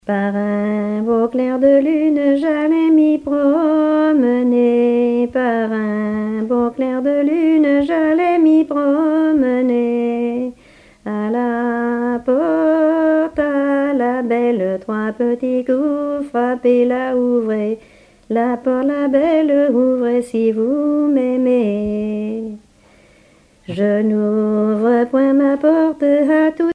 Genre laisse
Chansons traditionnelles